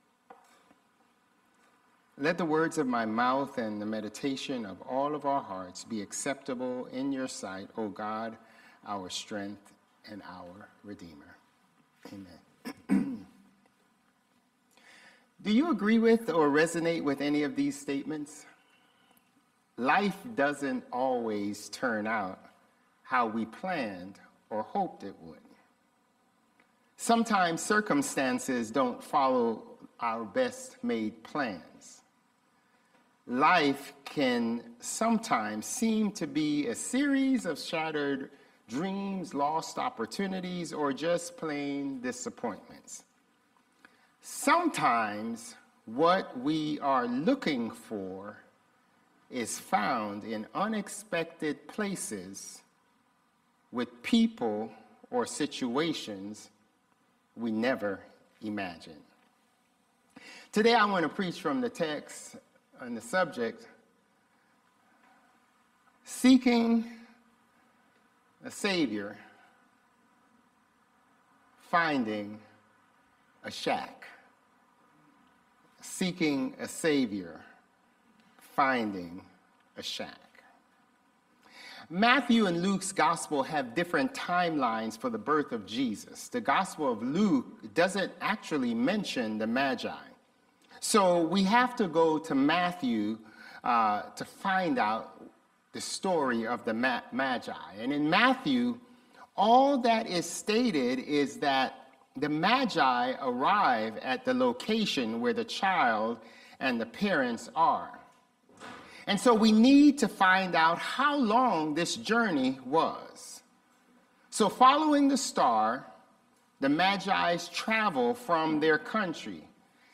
Sermons | Bethel Lutheran Church
December 22 Worship